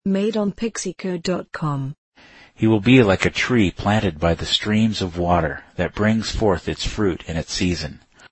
Make backward audio for unique sound effects in just a few clicks.
Reverse Audio